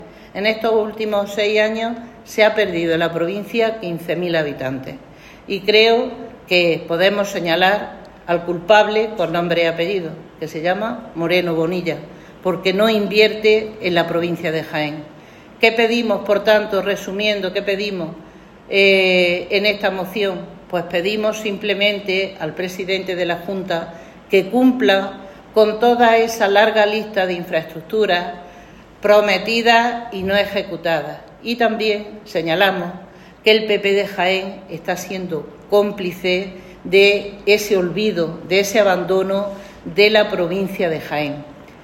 En rueda de prensa, la portavoz socialista de la Diputación acusó a la Junta de Andalucía de promover una “peligrosa asimetría territorial”, puesto que las inversiones no llegan a la provincia de Jaén pero sí a otras de Andalucía.
Cortes de sonido